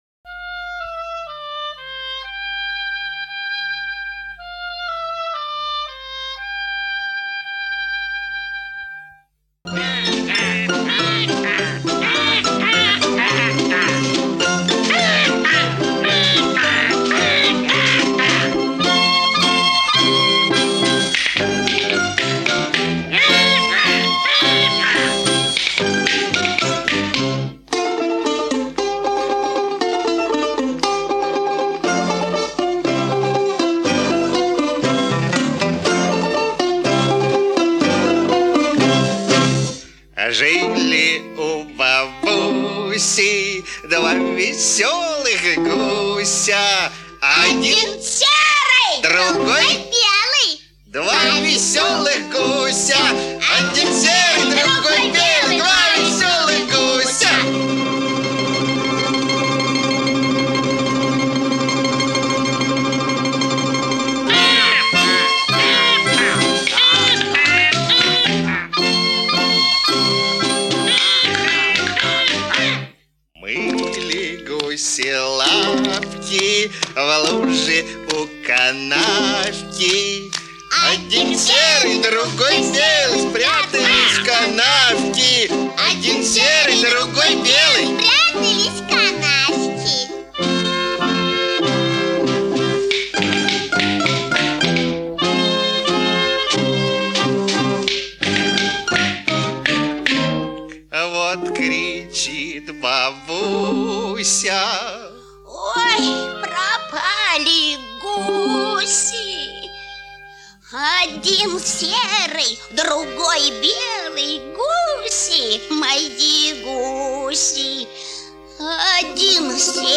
Детская песня простая и понятная всем.